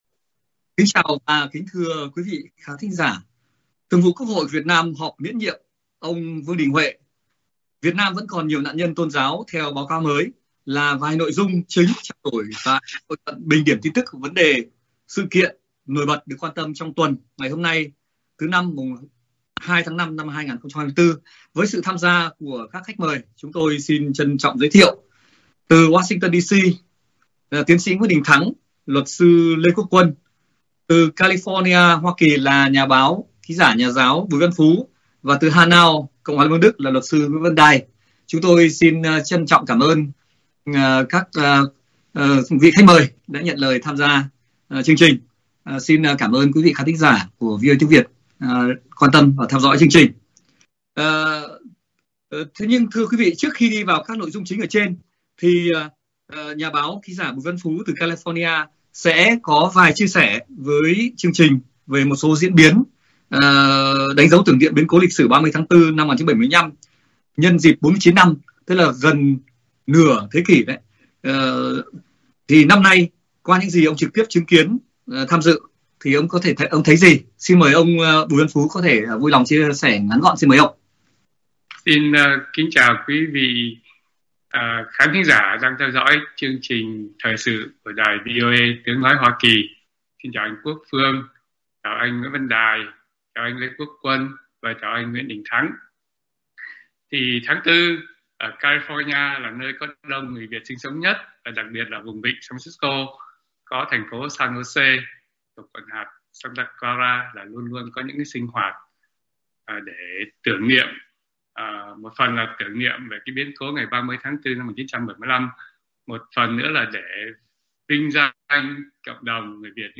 Các luật sư, nhà báo, nhà quan sát thời sự, chính trị bình luận, phân tích một số diễn biến, tin tức, vấn đề thời sự chính trong tuần, trong đó có việc Thường vụ Quốc hội Việt Nam họp miễn nhiệm Chủ tịch QH Vương Đình Huệ, trong khi ghế Chủ tịch nước còn bỏ trống.